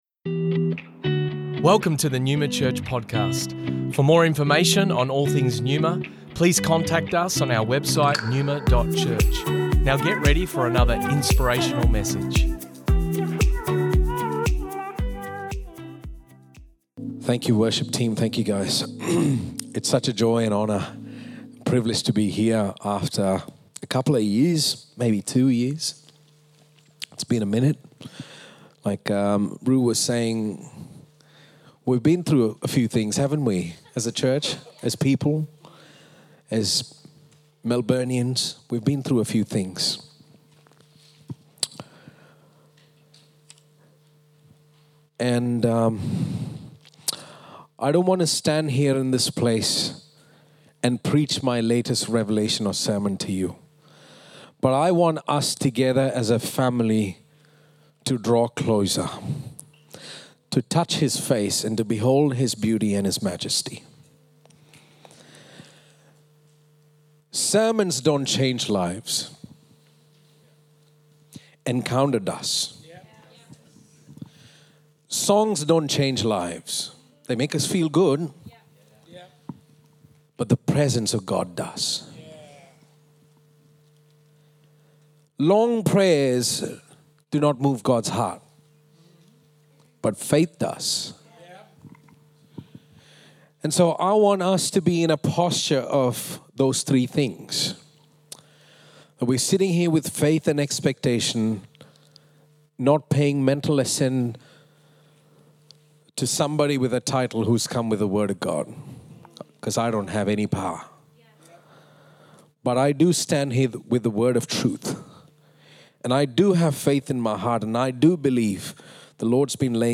Originally recorded at Neuma Melbourne West On the 13th of July 2023